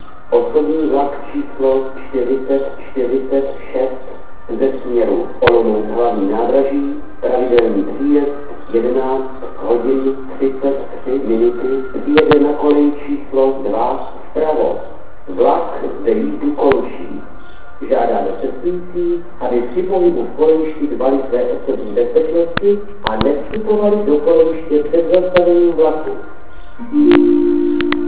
Tak jsem se tedy vydal do žst. NEZAMYSLICE a tohle tam vzniklo. Kvalita je naprd, ale jako ukázka si myslím, že je to dobré.
NEZAMYSLICE - Os 4046_přijede.WAV (113.2 k)